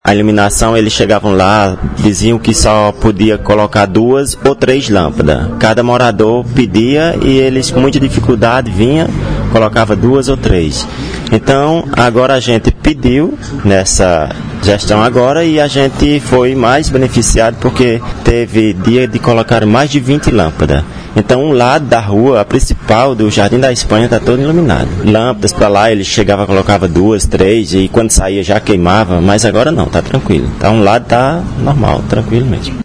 • Fala do morador do Jardim Espanha